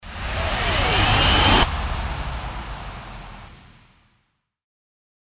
FX-1504-WIPE
FX-1504-WIPE.mp3